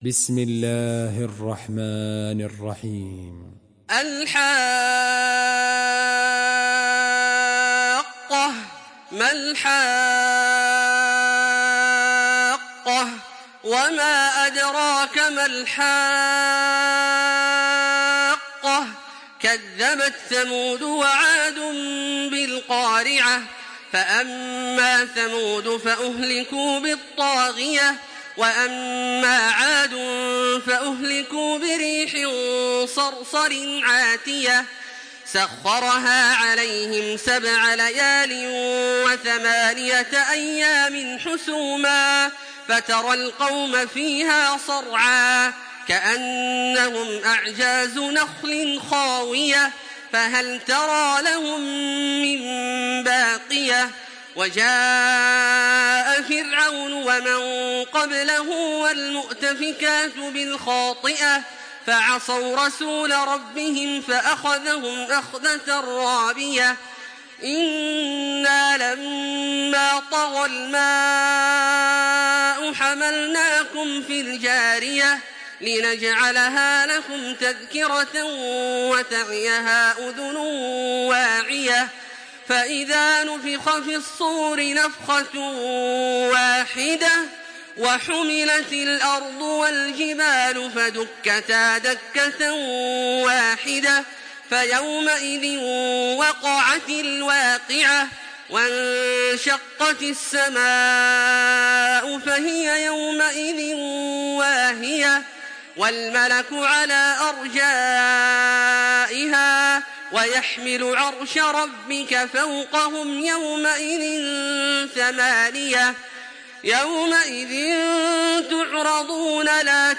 تراويح الحرم المكي 1426
مرتل حفص عن عاصم